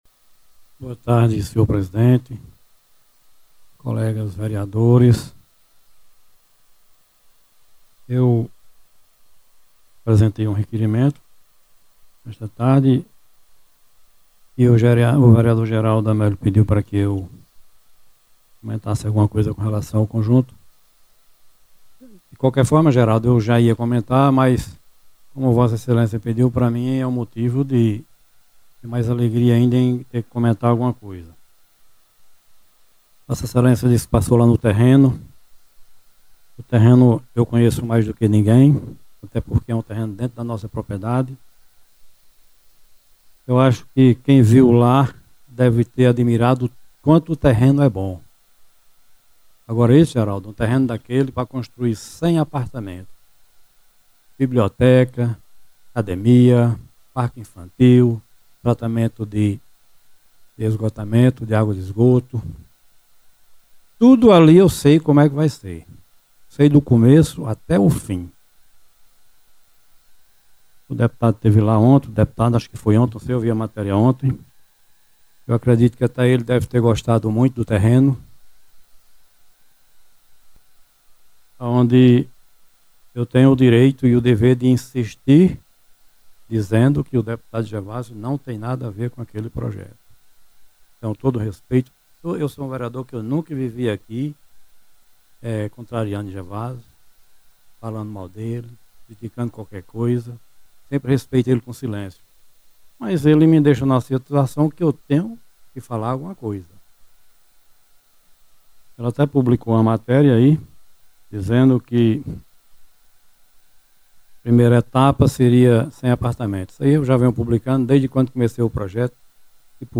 O vereador Cláudio de Sinfrônio durante a Sessão Ordinária desta segunda-feira, dia 23 de fevereiro, veio a público esclarecer informações sobre a construção dos 100 apartamentos que serão erguidos em…